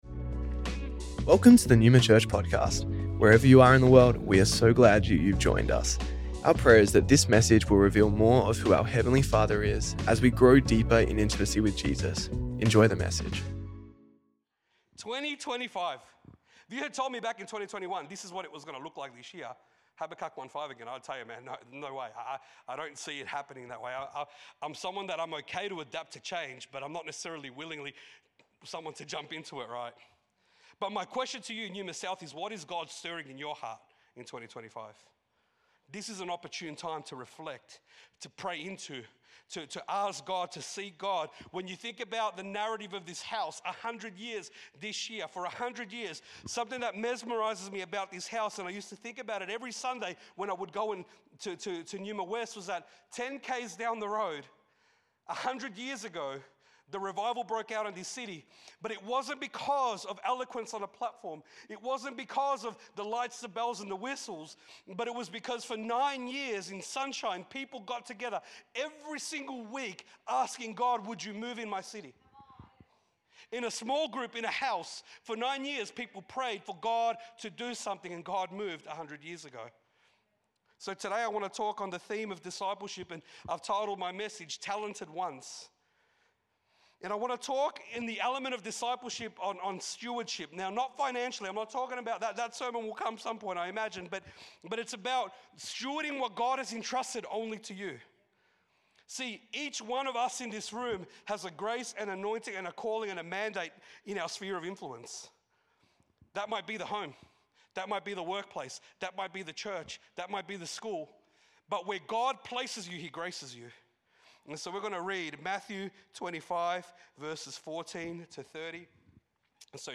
Neuma Church Melbourne South Originally recorded at the 10AM Service on Sunday 29th June 2025